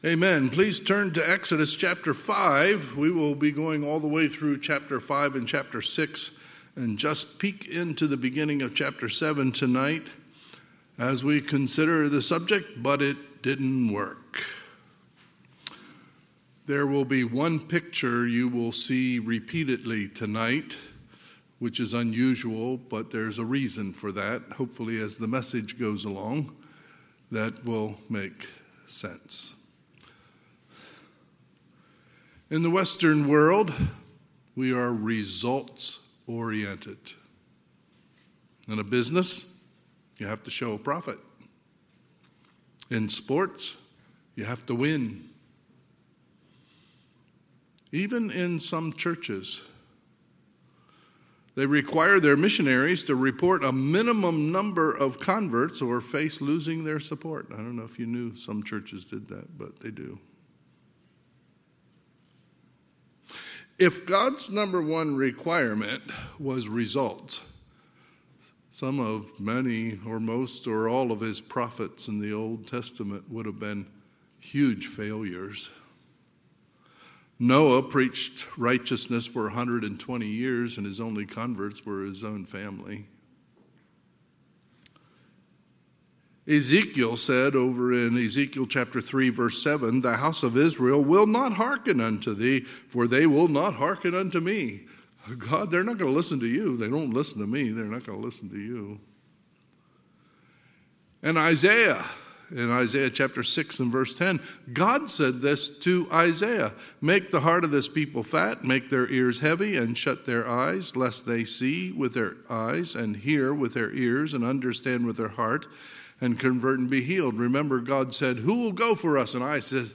2023 Sermons admin Exodus 5:1 – 7:6